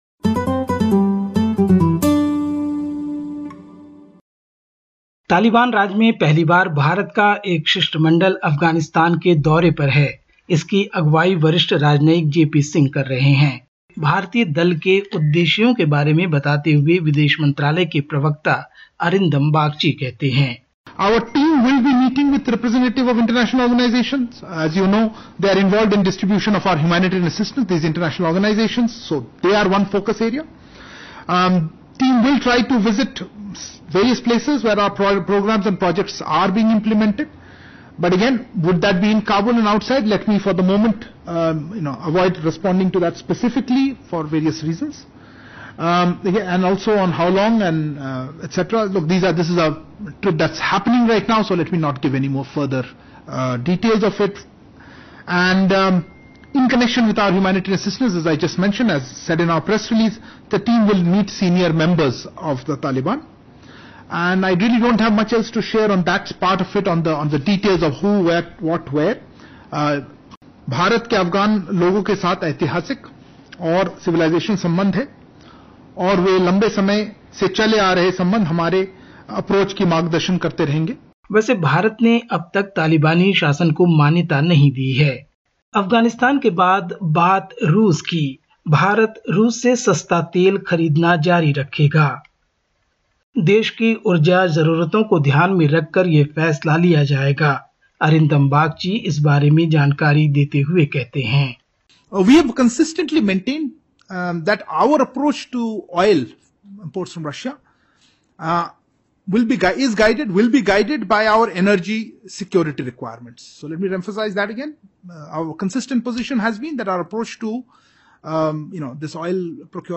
Listen to the latest SBS Hindi report in India. 03/06/2022